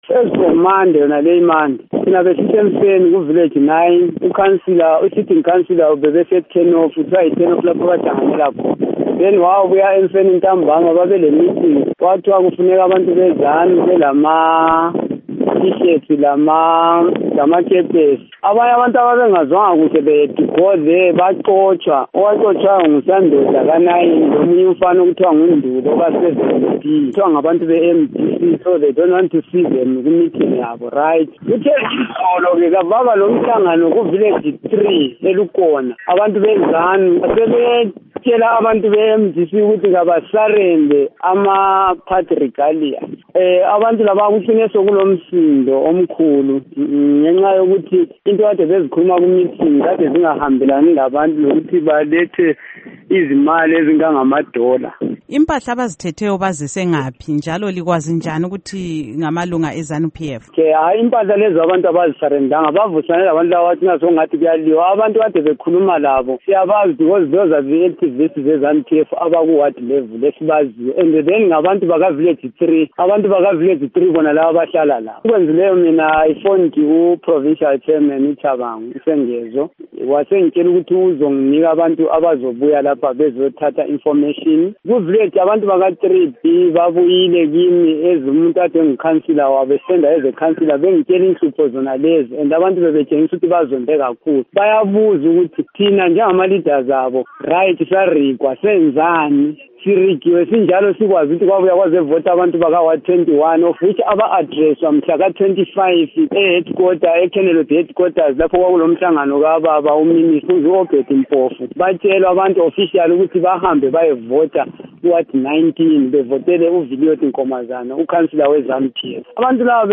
Ingxoxo Lesiphathamandla SeMDC-T